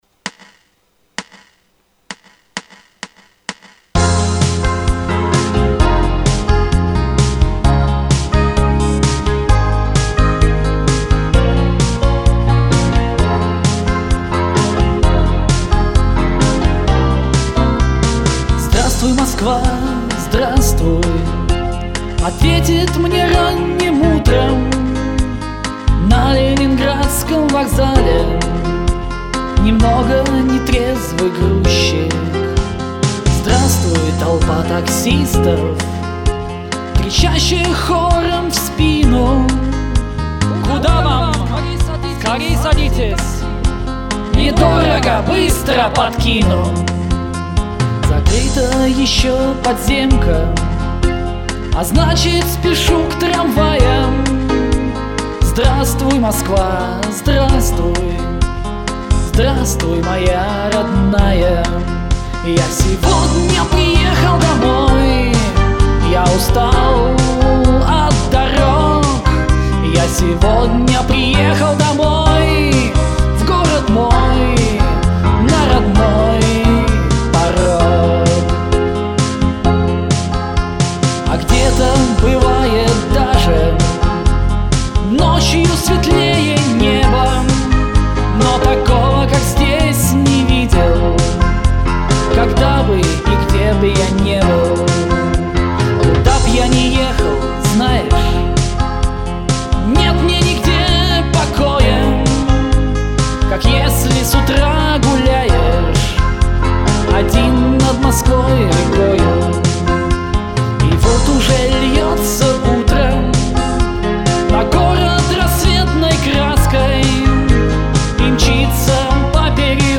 • Жанр: Арт-рок